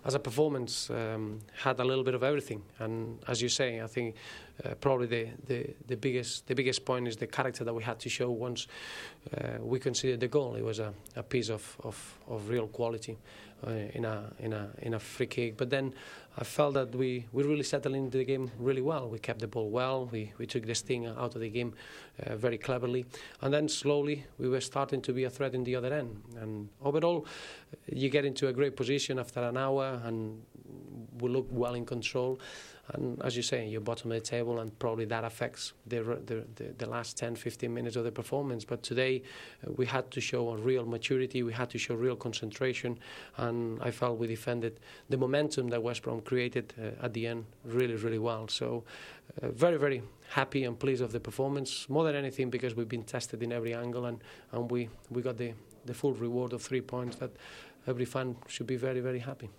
Post - Match Roberto Martinez's side beat West Brom 1 - 2. This is what he had to say to the BBC after the match